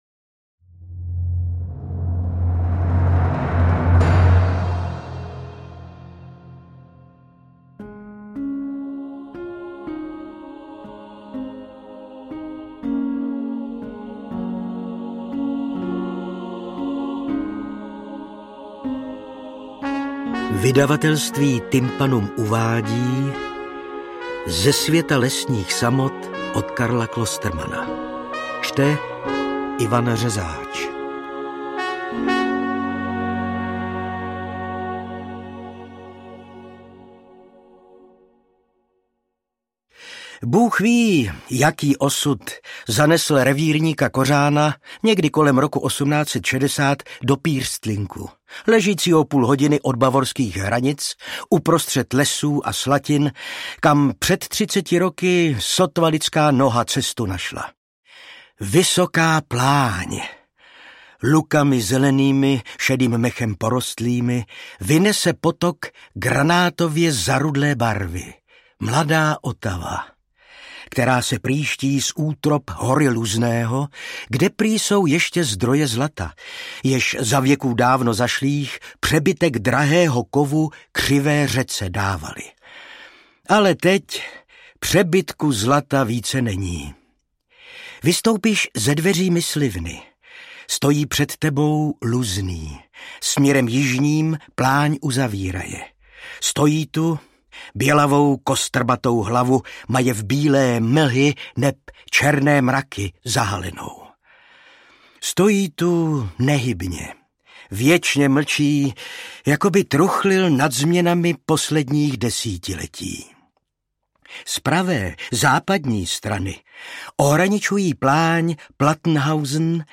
Interpret:  Ivan Řezáč
AudioKniha ke stažení, 36 x mp3, délka 12 hod. 7 min., velikost 668,6 MB, česky